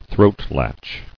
[throat·latch]